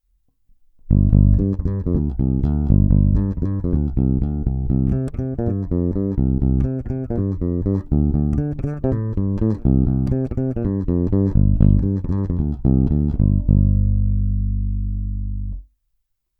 Nahrávky jsou provedeny rovnou do zvukovky a dále kromě normalizace ponechány bez úprav.
Na 2EQ 93 jsem dal basy skoro naplno a výšky pocitově někde kolem střední polohy.